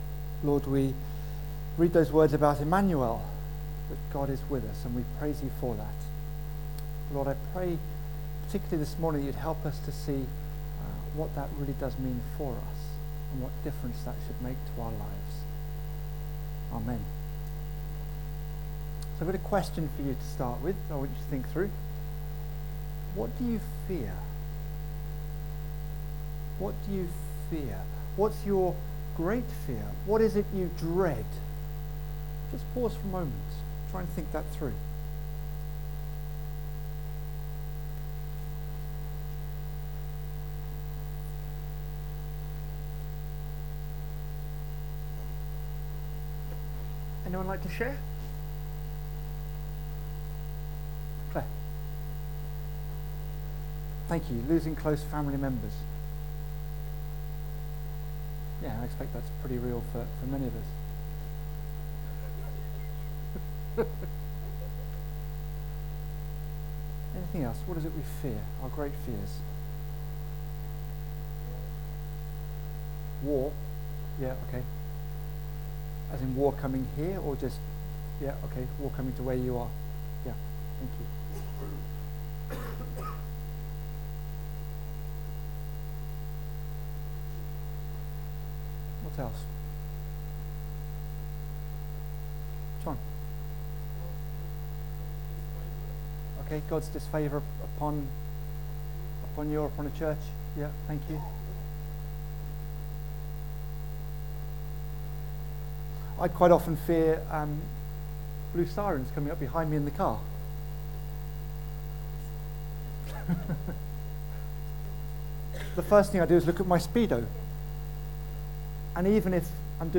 Single Sermon | Hope Church Goldington